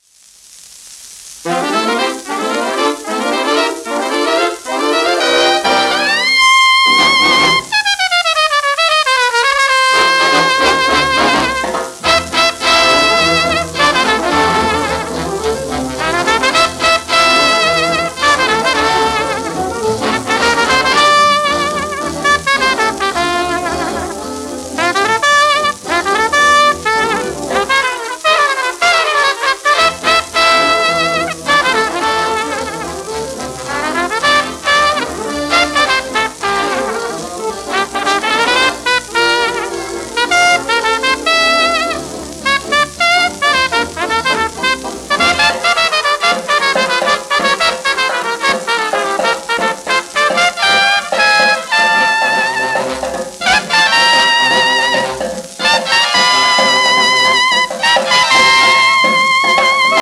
1938年の録音。